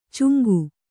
♪ cuŋgu